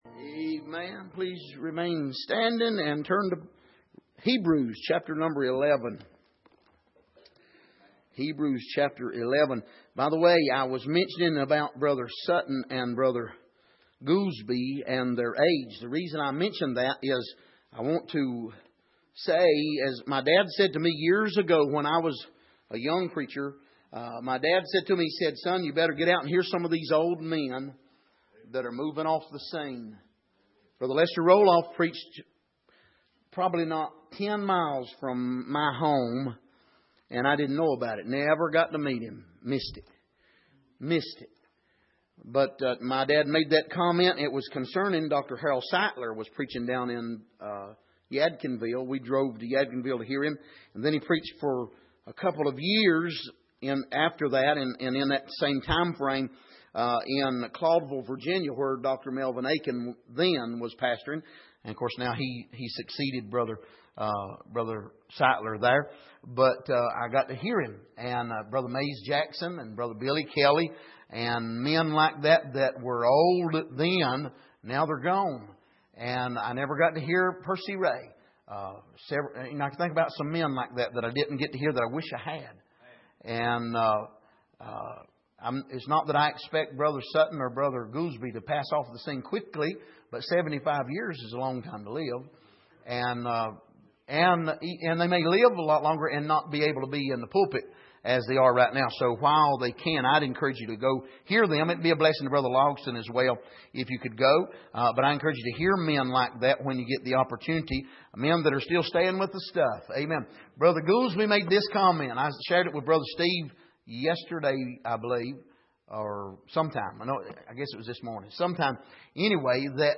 Miscellaneous Passage: Hebrews 11:7-16 Service: Midweek